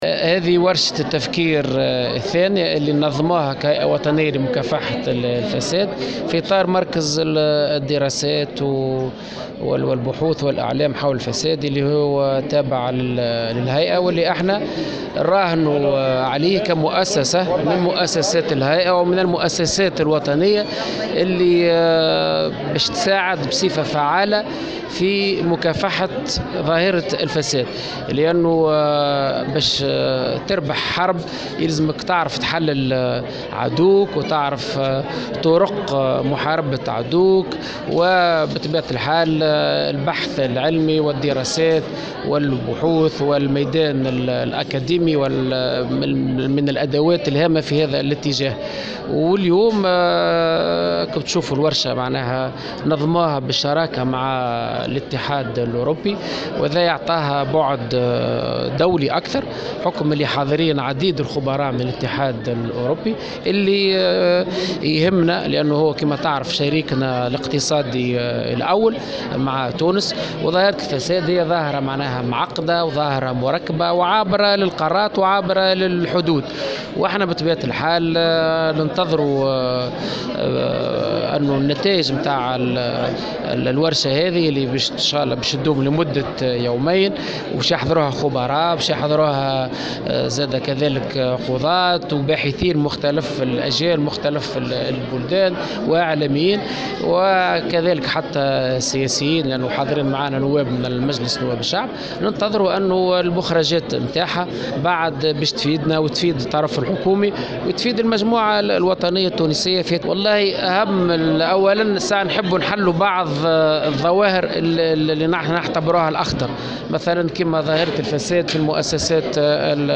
أكد رئيس هيئة مكافحة الفساد شوقي الطبيب في تصريح لمراسلة الجوهرة "اف ام" اليوم الخميس 7 سبتمبر 2017 أن الورشة التي تنظمها الهيئة اليوم في إطار مركز الدراسات والبحوث والإعلام حول الفساد التابع للهيئة والذي يراهن عليها كمؤسسة وطنية التي ستساهم بصفة فعالة في مكافحة ظاهرة الفساد.